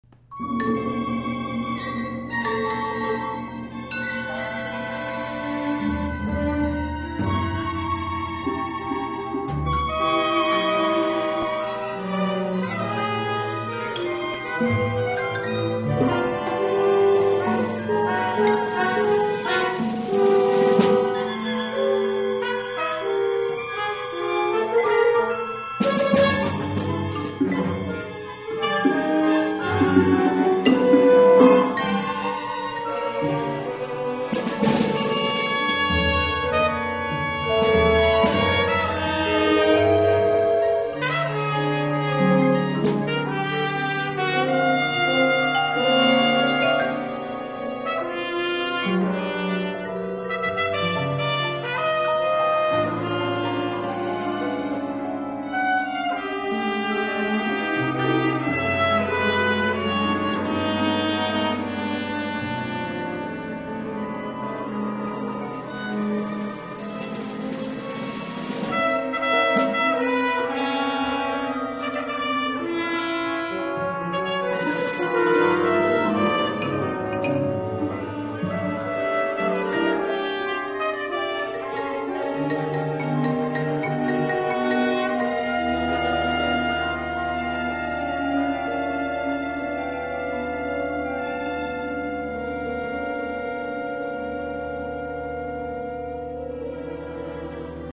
New Music Reading Sessions